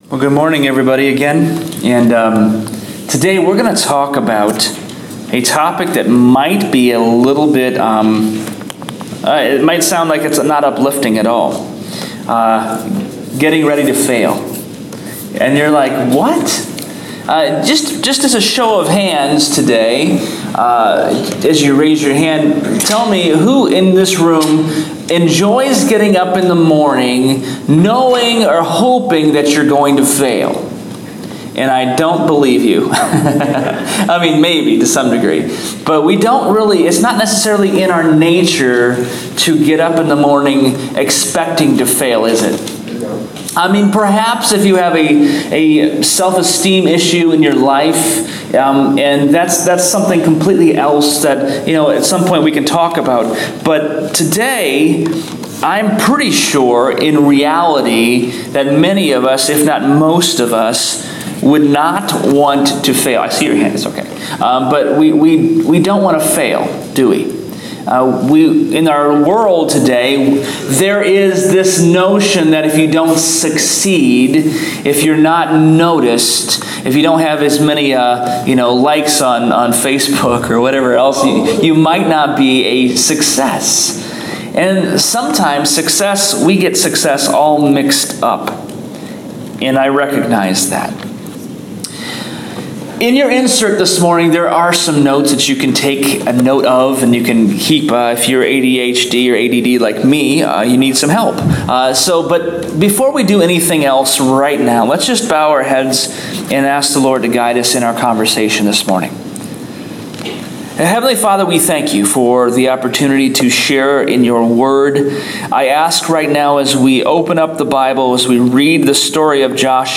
Sermon Podcast – “Getting Ready to Fail”